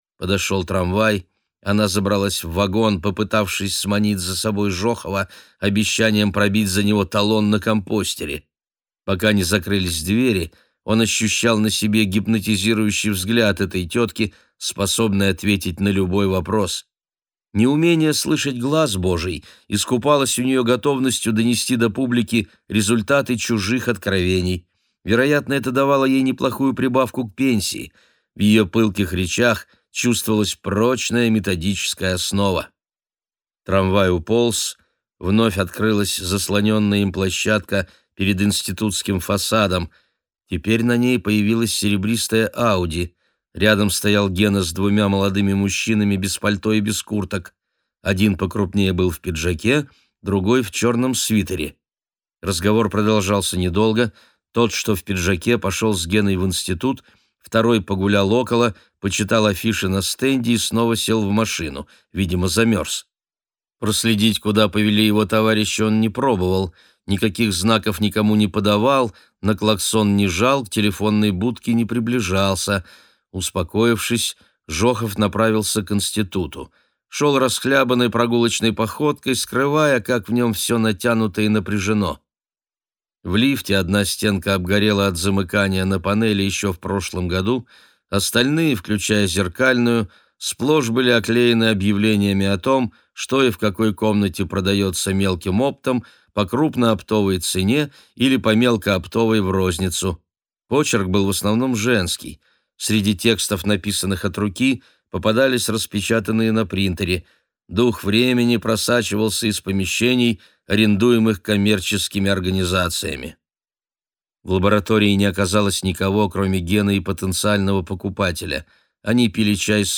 Аудиокнига Журавли и карлики | Библиотека аудиокниг